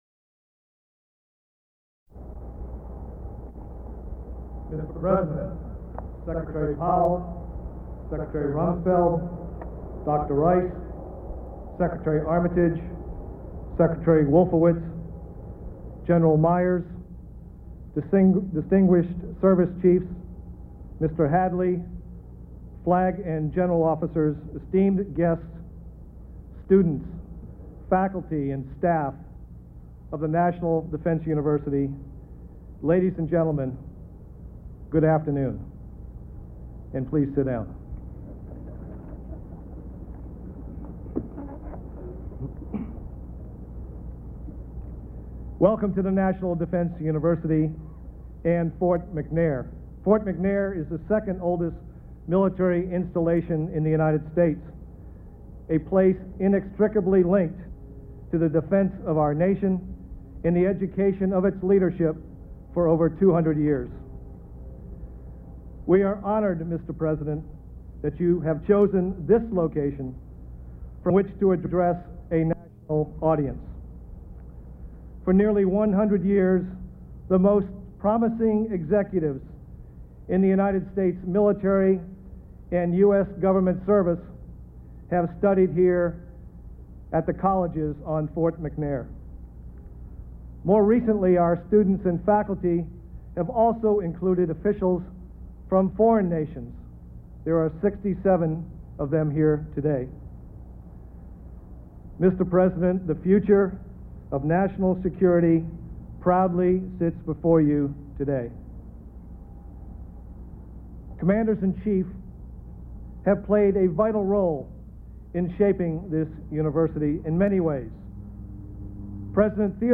President George W. Bush delivers a speech announcing his intent to pursue a national missile defense program. Bush explains that while the nuclear threats of the Cold War are not as present today, the threats still remain in unpredictable ways and places.
Held at the National Defense University in Fort Lesley McNair, Washington D.C.